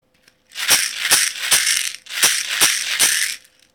マラカス ひょうたん底 【アフリカ ブルキナファソ産】 (f070-10)
小さな木の実が乾いたシャカシャカ音を奏でます♪
この楽器のサンプル音